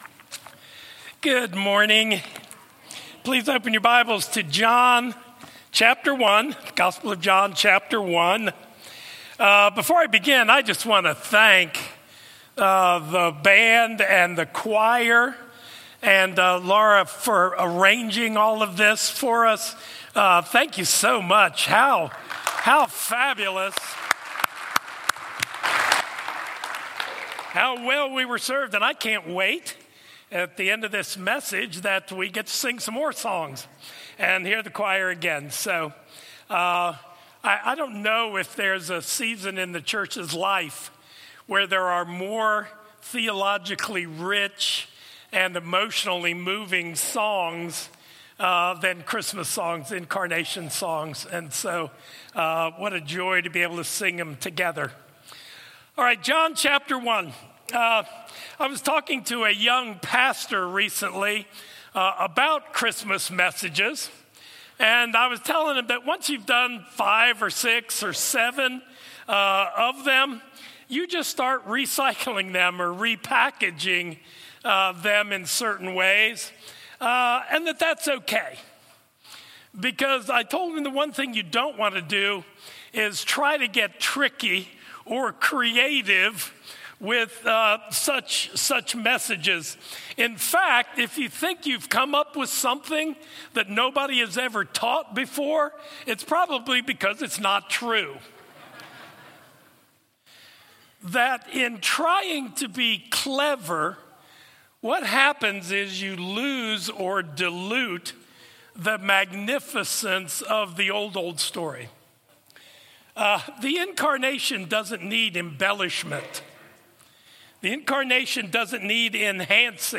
A message from the series "Advent 2023."